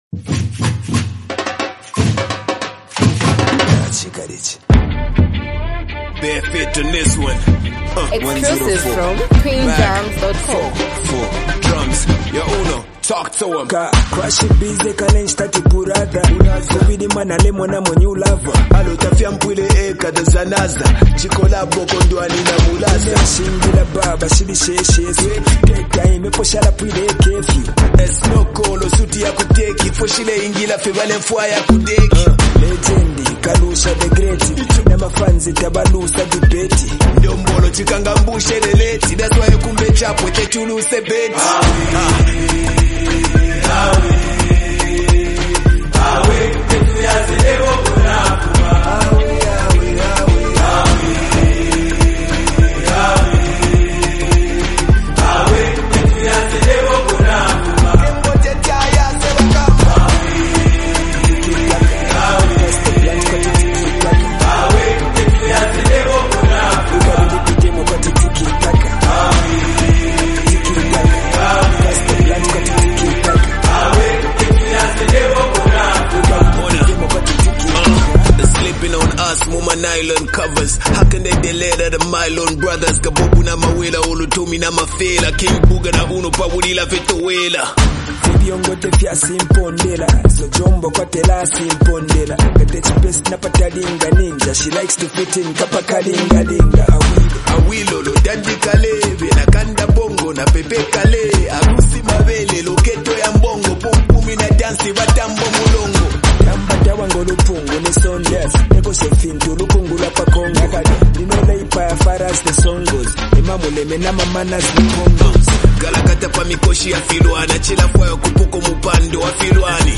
Highly multi talented act and super creative rapper